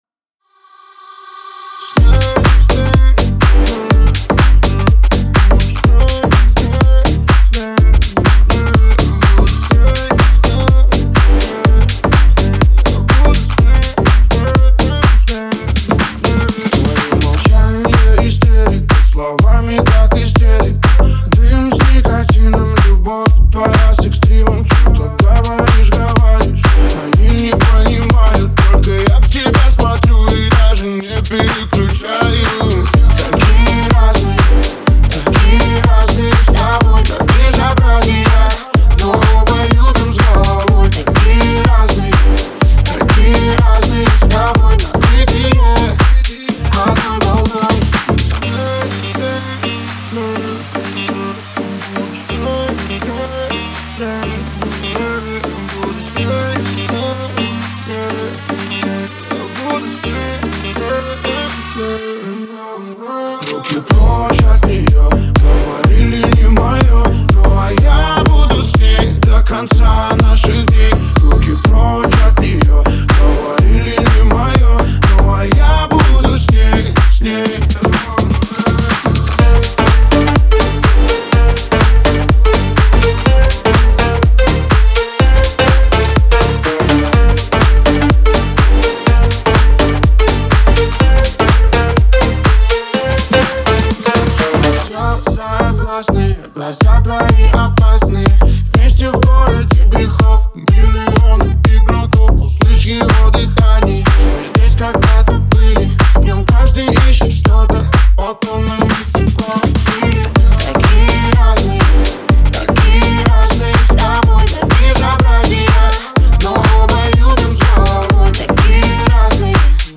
это динамичная электронная композиция